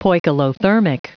Prononciation du mot poikilothermic en anglais (fichier audio)
Prononciation du mot : poikilothermic